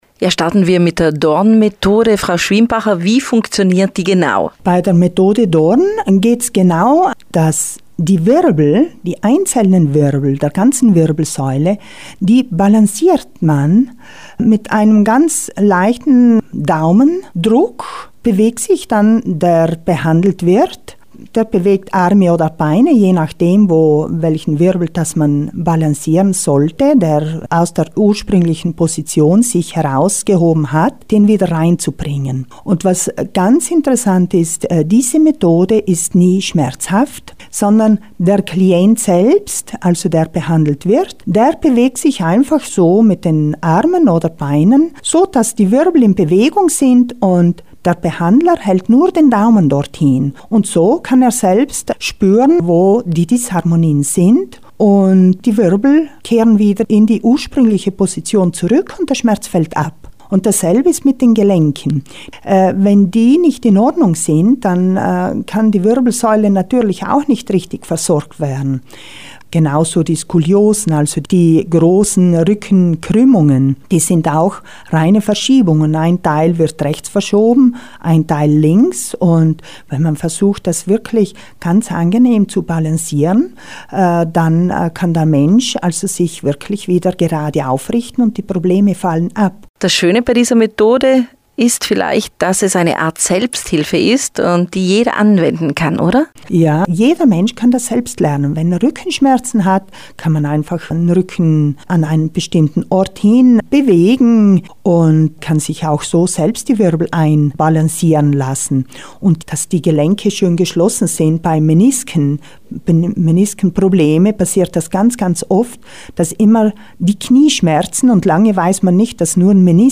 Interview zur Dorn-Methode und Breuss-Massage